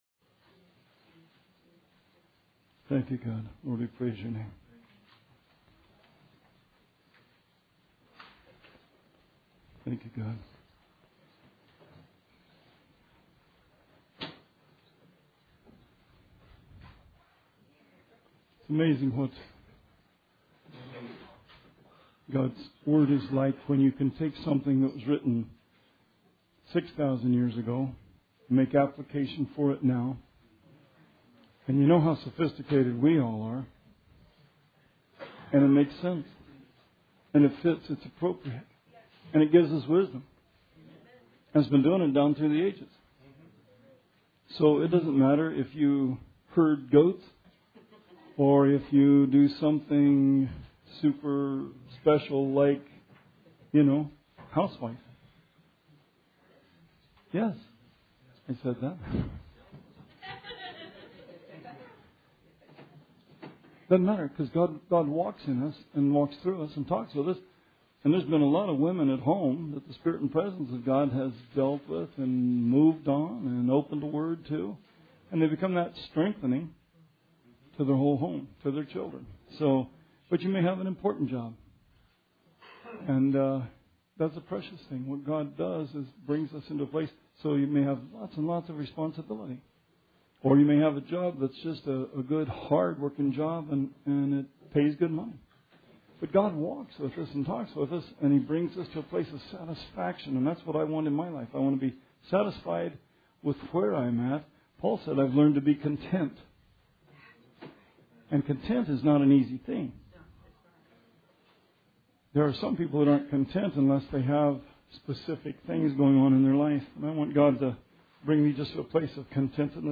Sermon 12/24/16